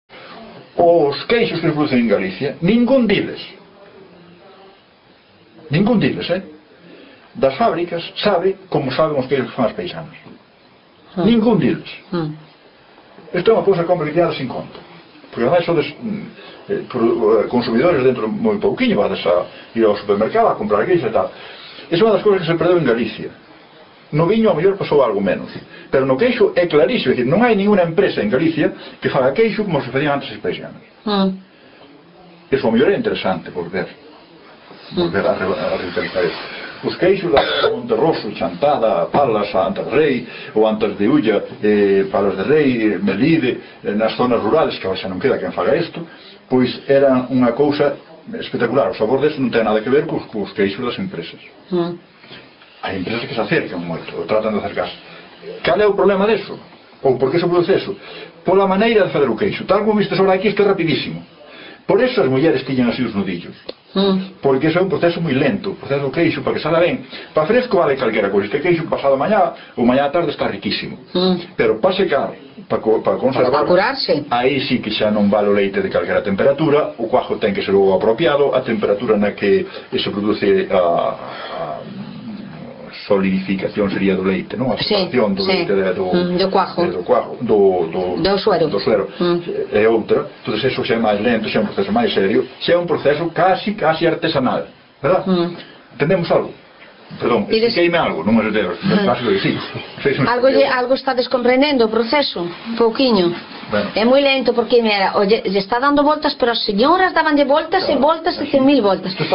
A profesora explica a elaboración do queixo